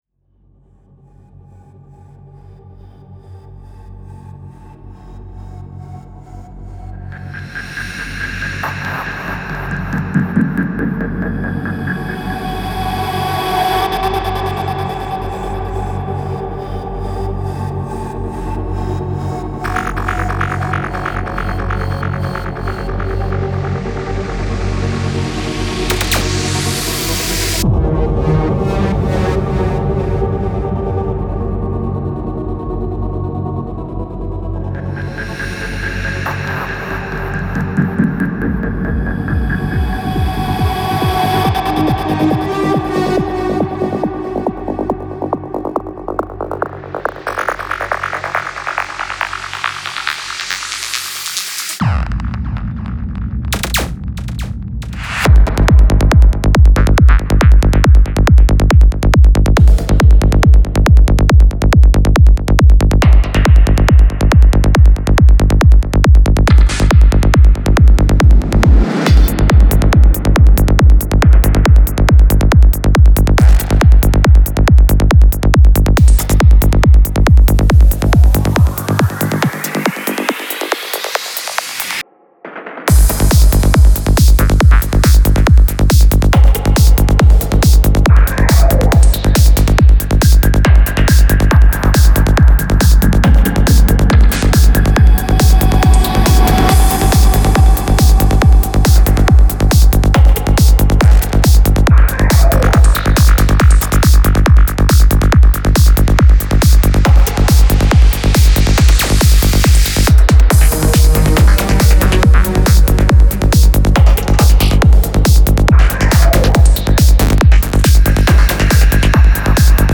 Файл в обменнике2 Myзыкa->Psy-trance, Full-on
Стиль: Psy Trance